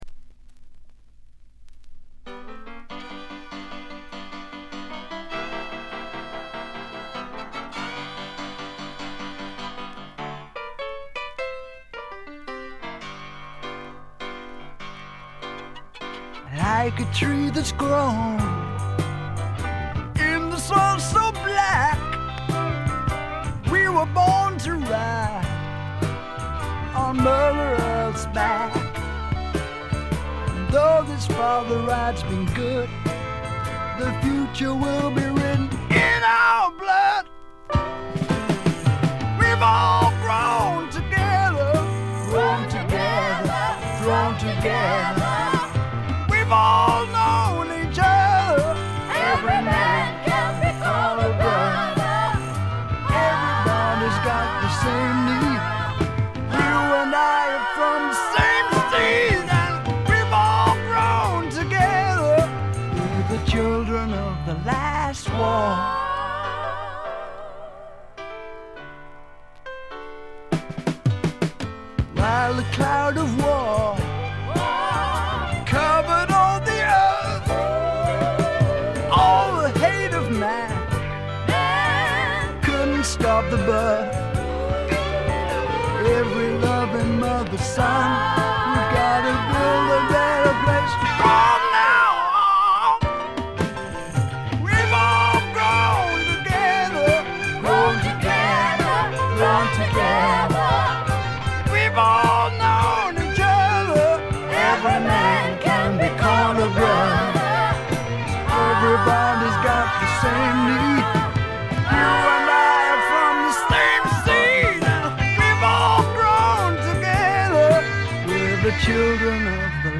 軽微なチリプチが出ていますがほとんど気にならないレベルと思います。
搾り出すような激渋のヴォーカルがスワンプ・サウンドにばっちりはまってたまりません。
試聴曲は現品からの取り込み音源です。